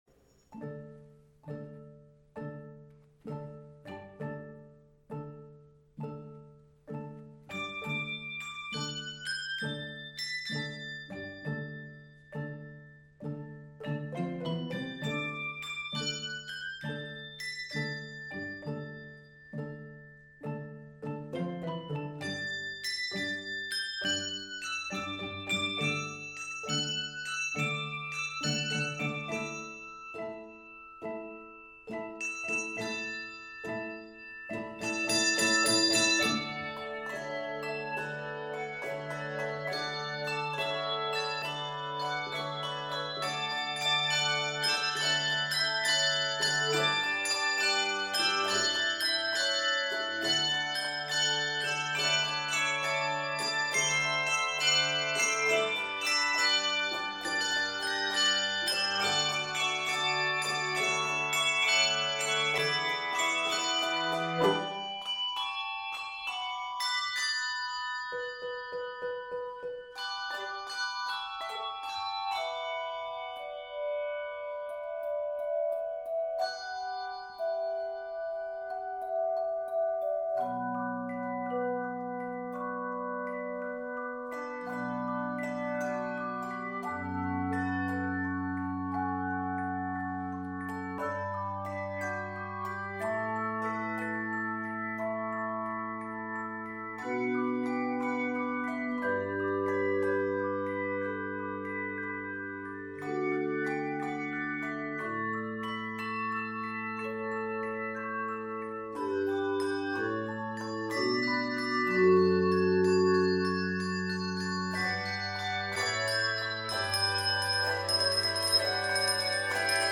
A delicate malleted figure opens this dance-like arrangement
Keys of D Major and F Major.
Octaves: 3-6